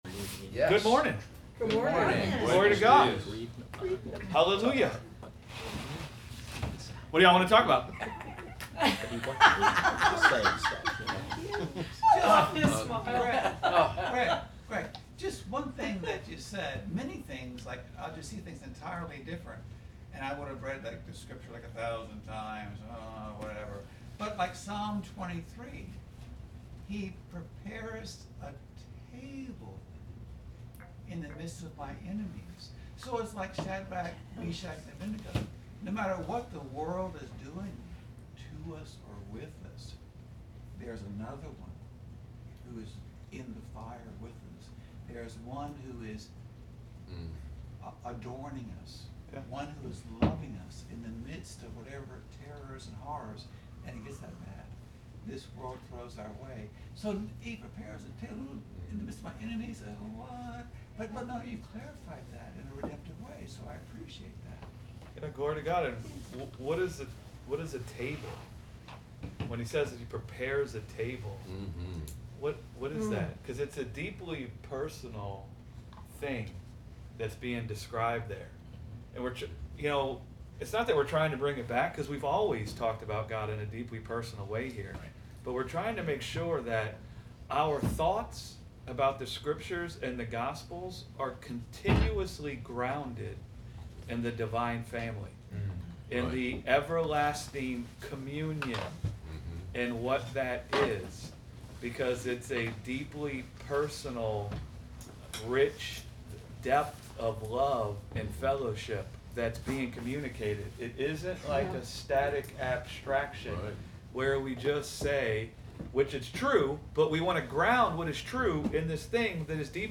Understanding the Trinity and Eternal Family | Sunday Bible Study On Divine Communion - Gospel Revolution Church